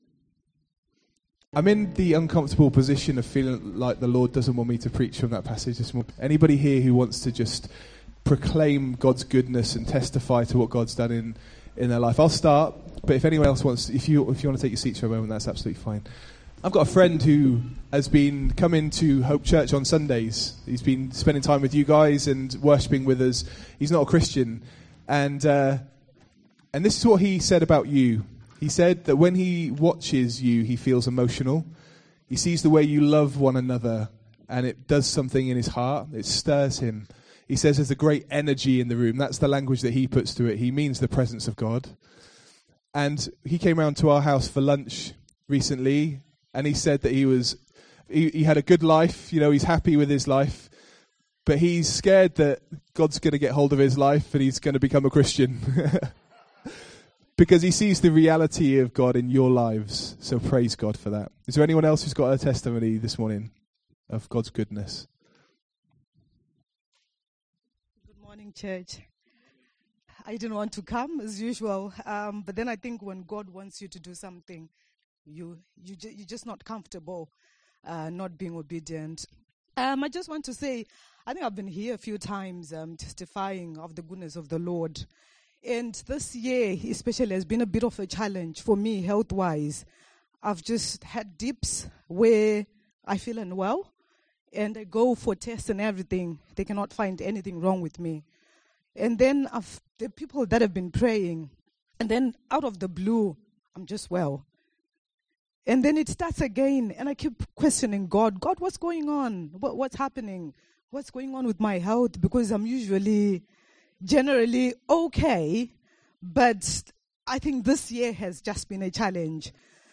Today instead of the planned sermon we have some powerful testimonies of God-changing lives.